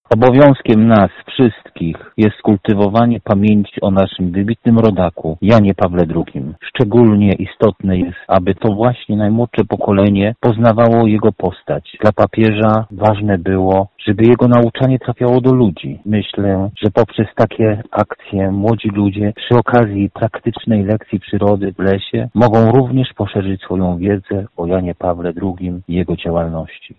Mówi poseł Fryderyk Kapinos.